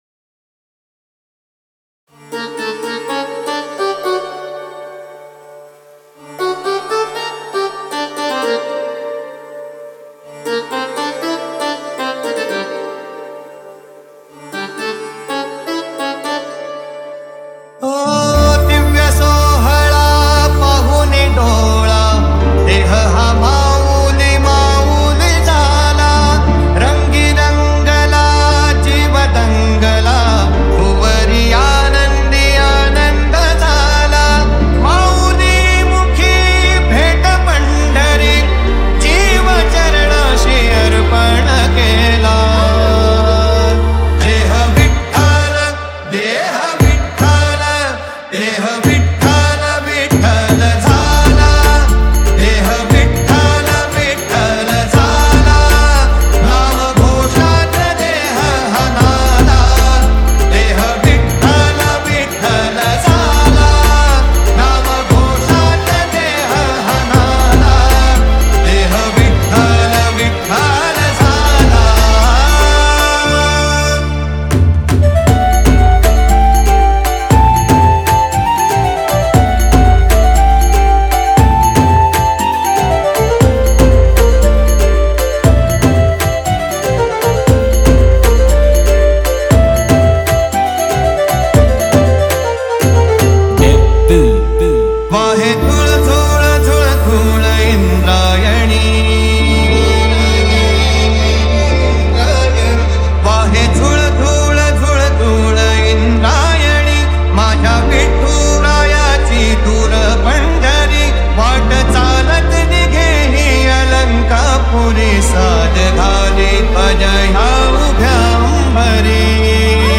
Marathi Dj Single 2025
Marathi Sound Check 2025